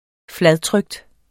Udtale [ ˈflaðˌtʁœgd ]